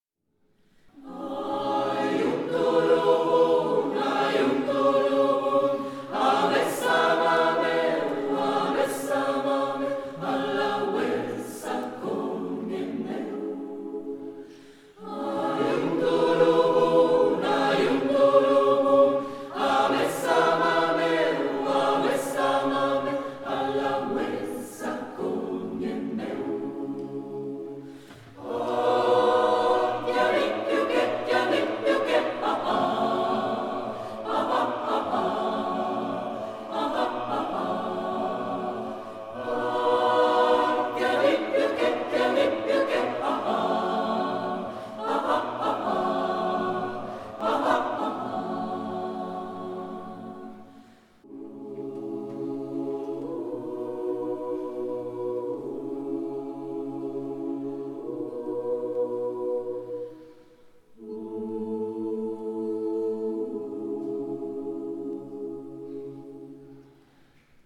Canzone su melodia tradizionale Mapuche
Musica ed elaborazione per coro Jeremias Zuñiga
Associazione Culturale Coro Hispano-Americano di Milano